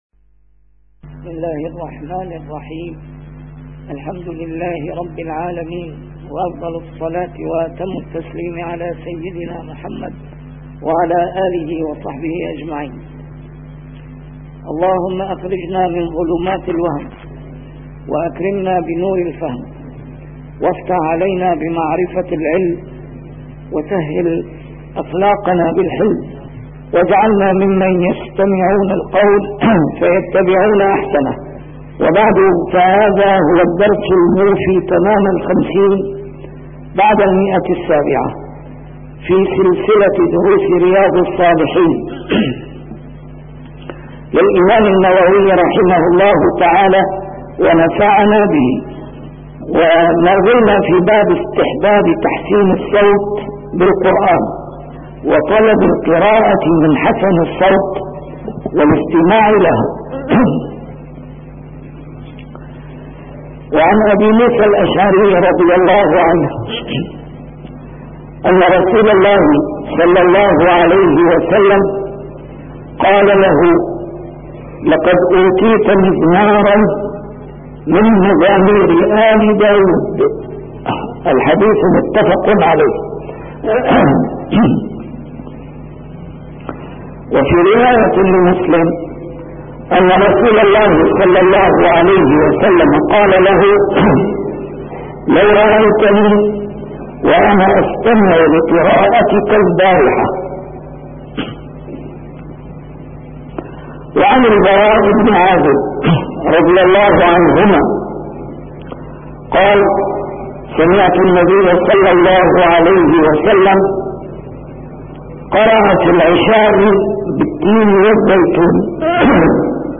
شرح كتاب رياض الصالحين - A MARTYR SCHOLAR: IMAM MUHAMMAD SAEED RAMADAN AL-BOUTI - الدروس العلمية - علوم الحديث الشريف - 750- شرح رياض الصالحين: تحسين الصوت بالقرآن